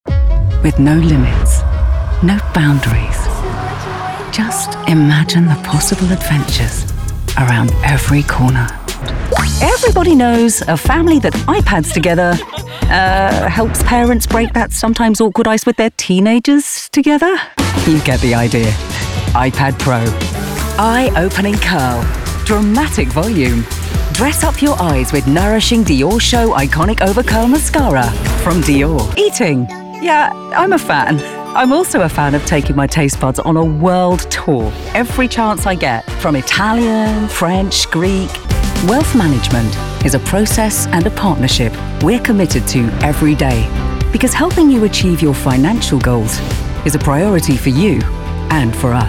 Hire Soprano-Pitched Voice Actors For Your Project
English (British)
Reassuring
Convincing
Clear